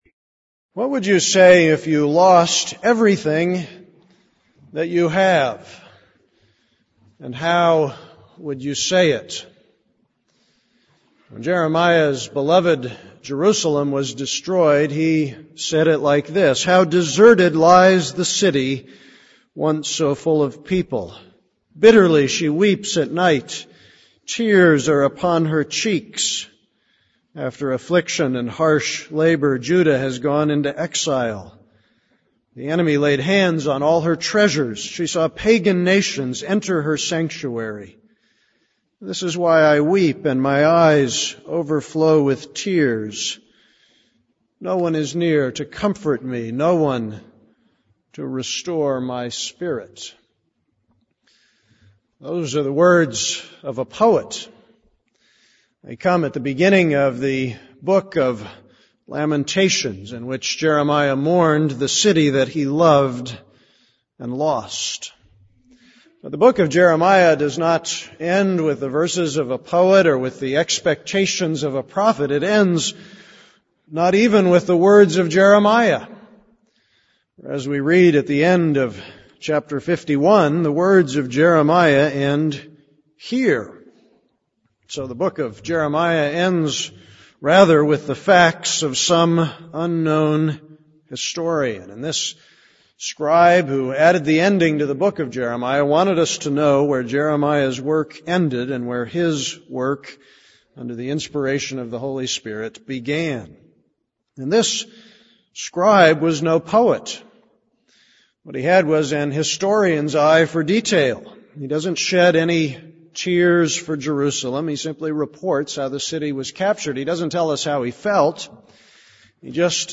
This is a sermon on Jeremiah 52:1-19, 34.